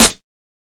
2000s Clicky Acoustic Snare Sound A Key 02.wav
Royality free snare sample tuned to the A note. Loudest frequency: 4923Hz